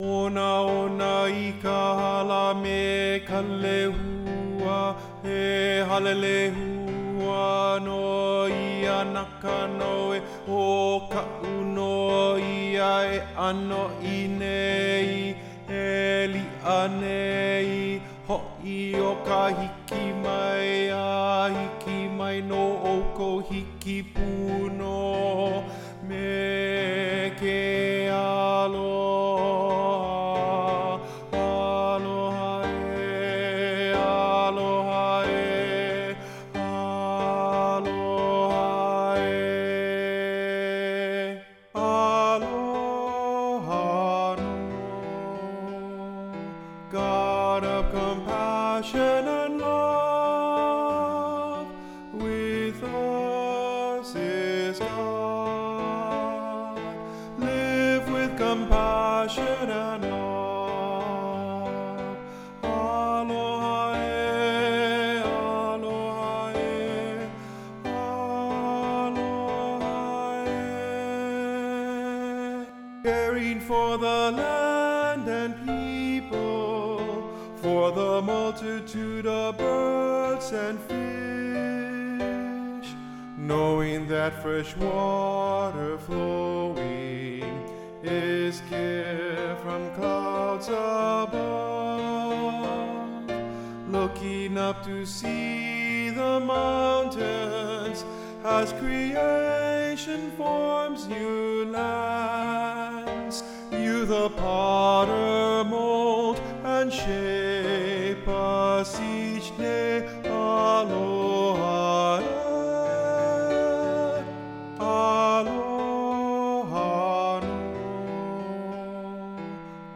Tenor   Instrumental | Downloadable